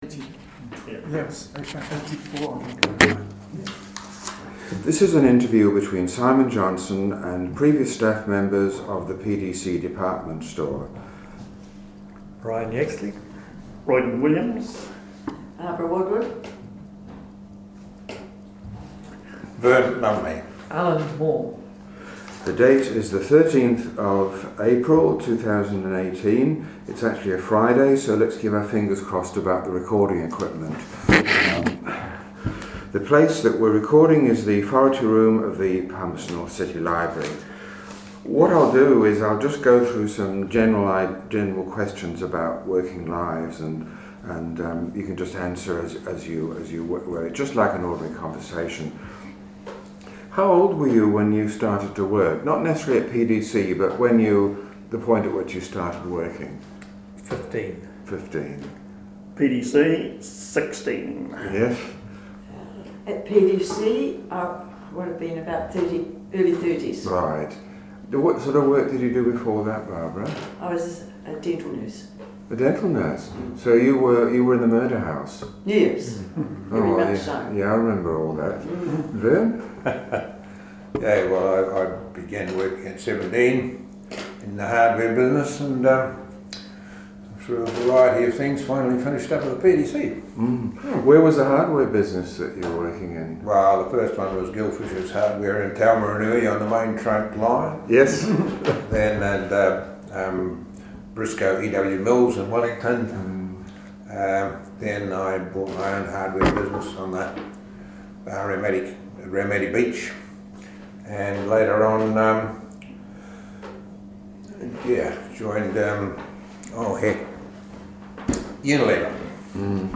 Oral Interview - Past Employees of the PDC Department Store, part 1
Location: Wharite Room, P.N. City Library. Notes: Recording slightly compromised by acoustics of the room and varying distance of interviewees from recorder.